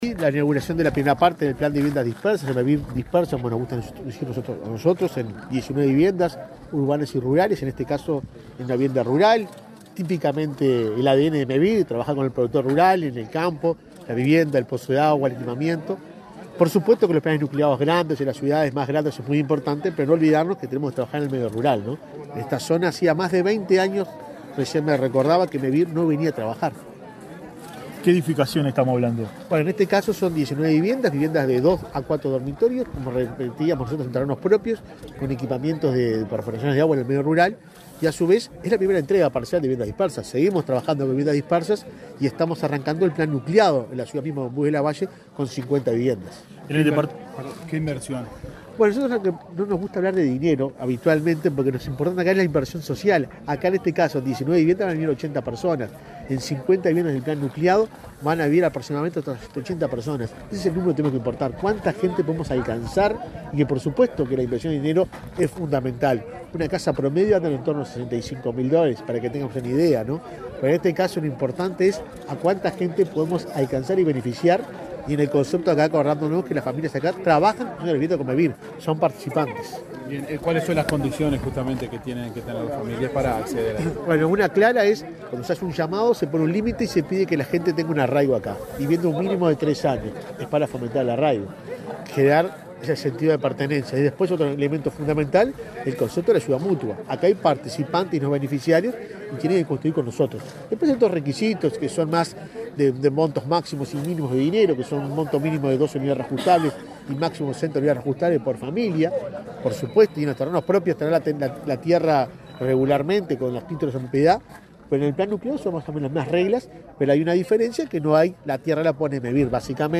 Declaraciones del presidente de Mevir, Juan Pablo Delgado
El presidente de la institución, Juan Pablo Delgado, dialogó con la prensa acerca del alcance de las obras.